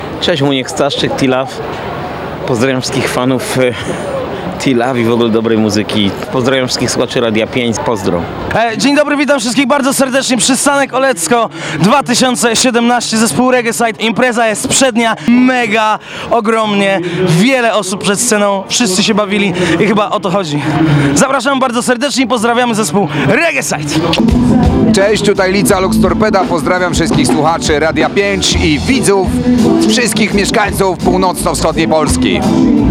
Jak co roku centrum miasta tętniło muzyka i zabawą.
Krótko mówiąc tłumy ludzi, świetna muzyka i dobra zabawa.